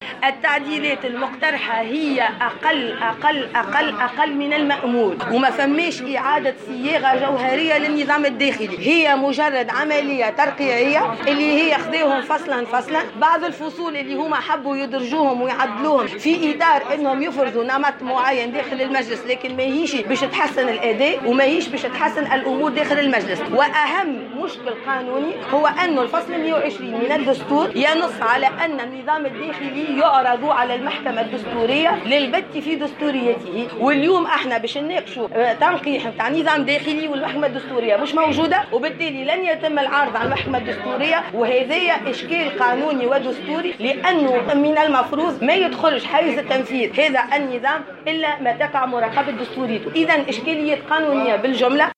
اعتبرت رئيسة كتلة الدستوري الحر عبير موسي في تصريح لـ "الجوهرة اف ام" اليوم الثلاثاء ان تنقيحات النظام الداخلي عملية ترقيعية.